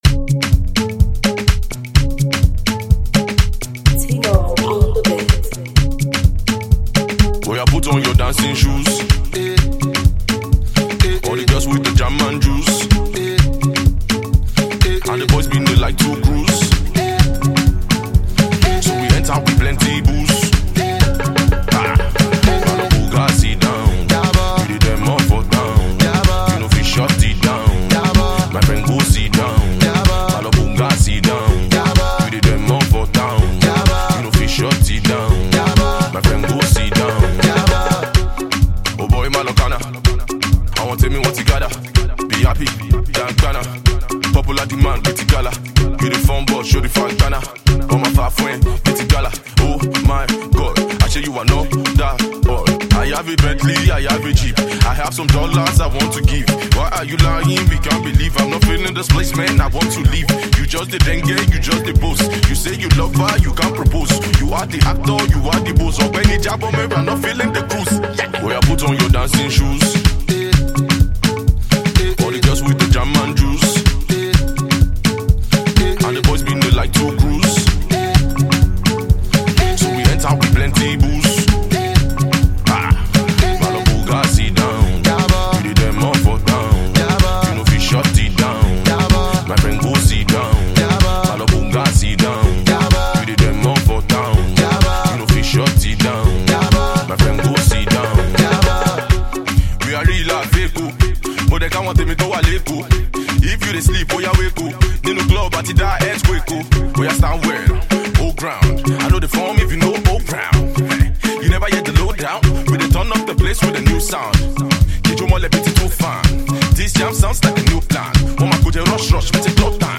Afro-dancehall vibe